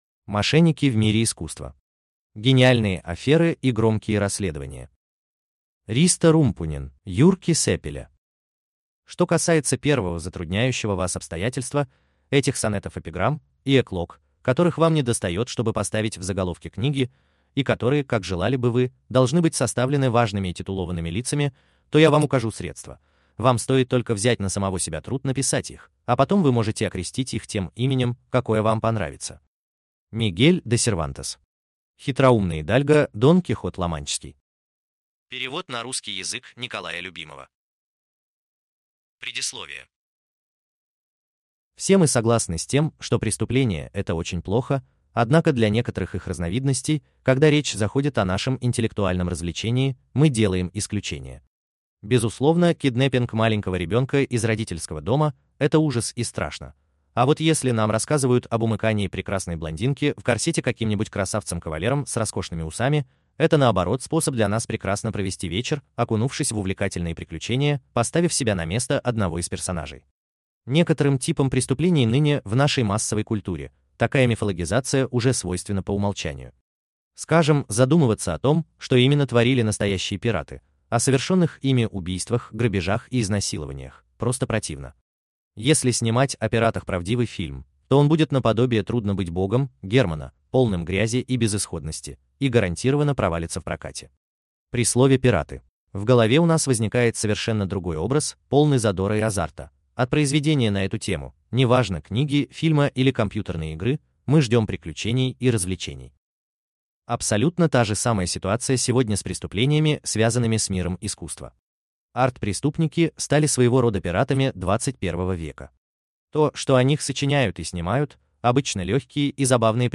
Аудиокнига Мошенники в мире искусства | Библиотека аудиокниг